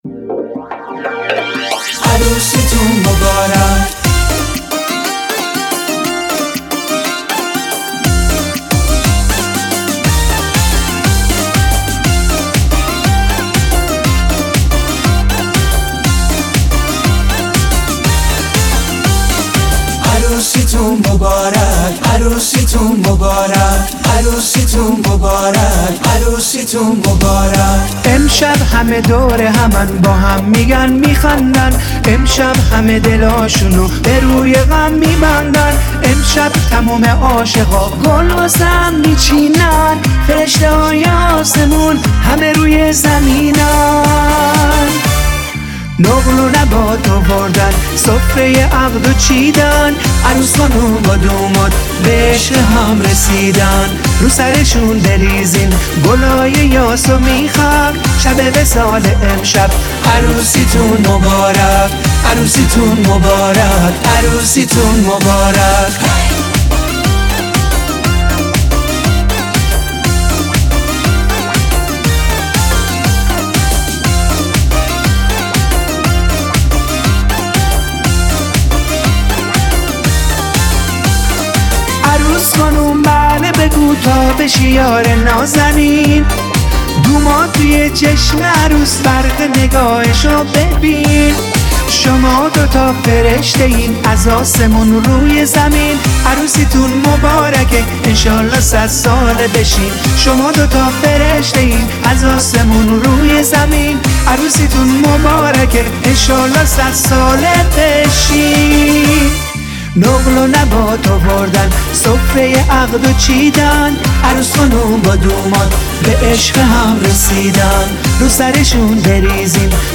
آهنگ شاد ایرانی